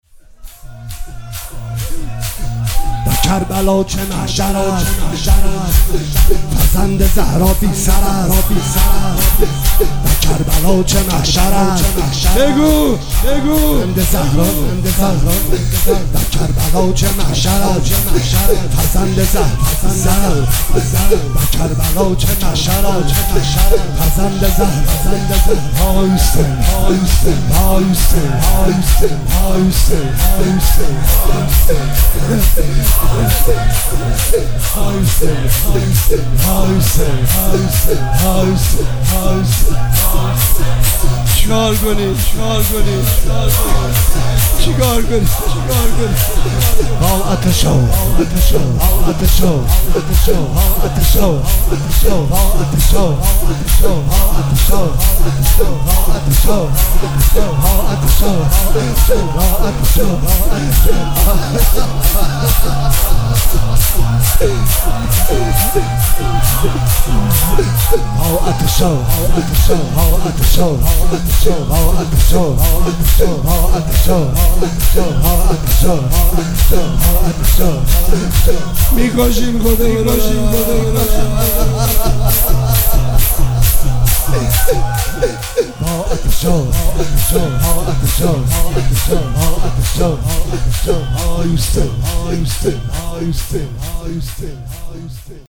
روز عاشورا 1404